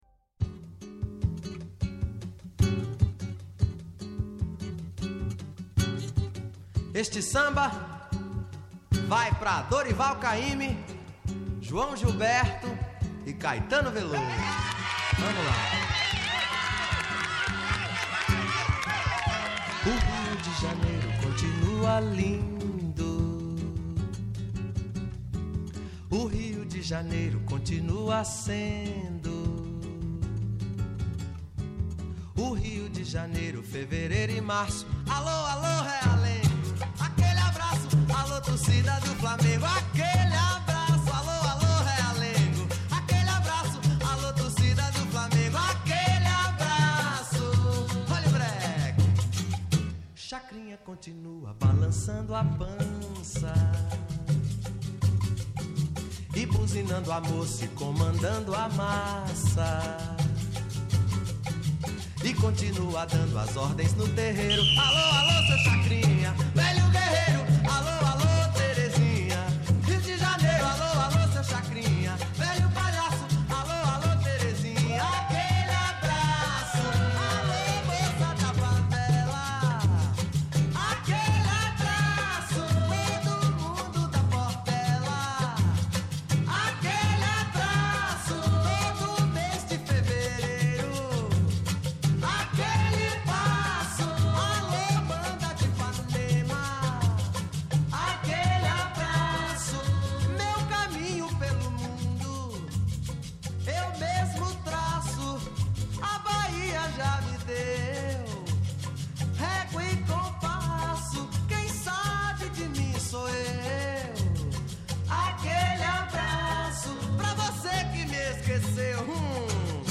Η Σωστή Ώρα στο Πρώτο Πρόγραμμα της Ελληνικής Ραδιοφωνίας